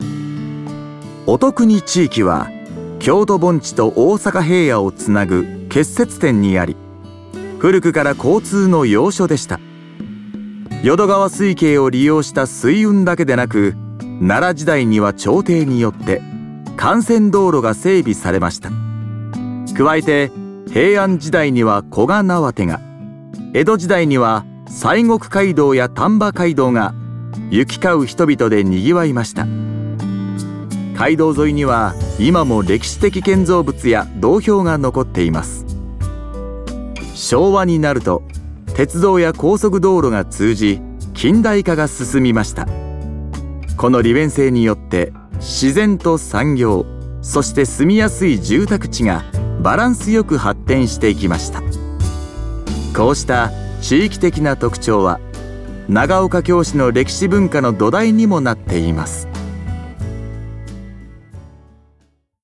音声ガイド